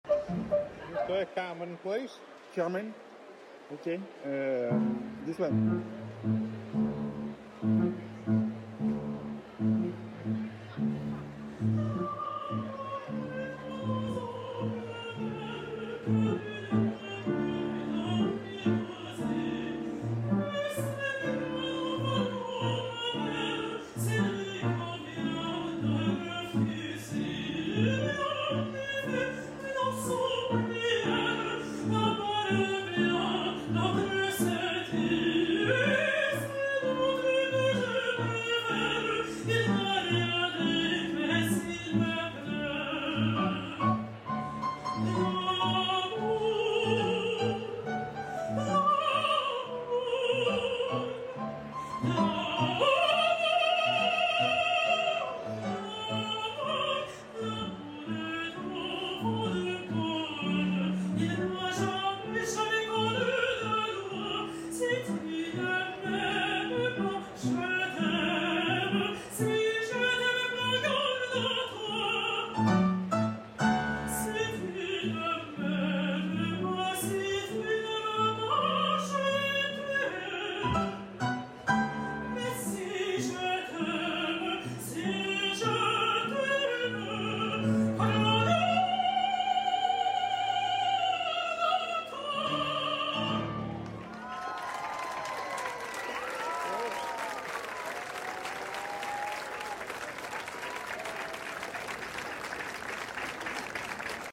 I was playing in London St Pancras station when this man asked me to play Carmen .. but SUDDENLY a passenger came with her suitcase and started singing 🤯